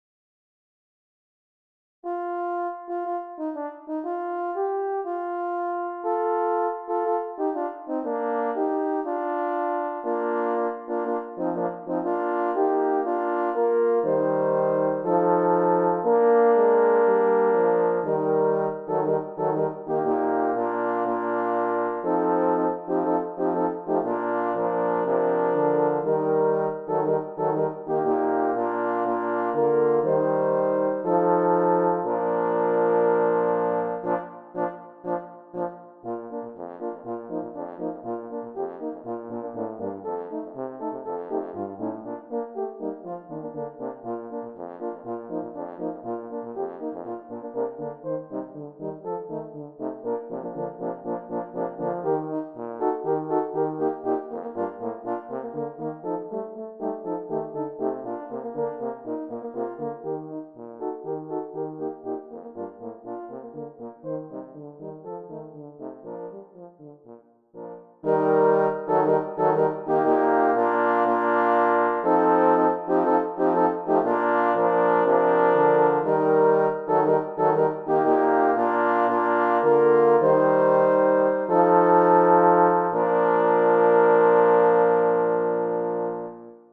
Ten down, forty to go. this is "AT CALVARY", a great old hymn that i have arranged for four horns.